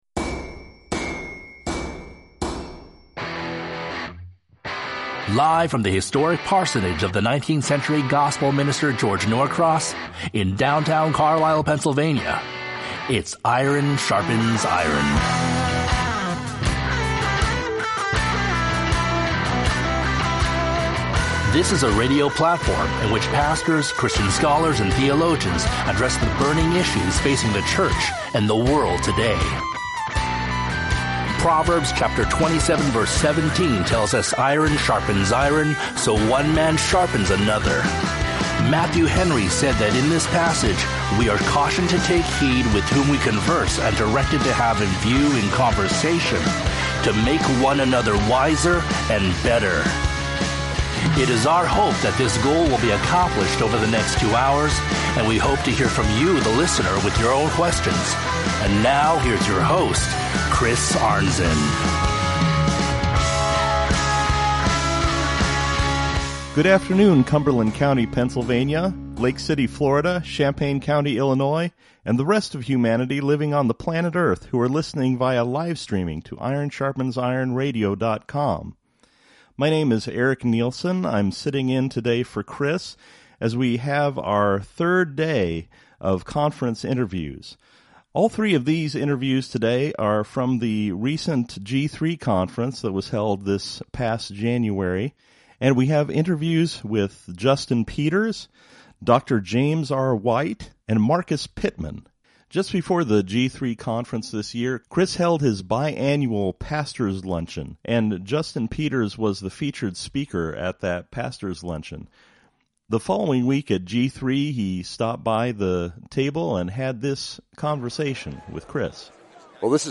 Featuring Interviews from the 2020 G3 Conference with